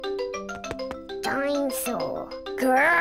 peppa-pig-portugues-brasil-_-dinossauros-_-hd-_-desenhos-animados.mp3